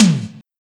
TOM03.wav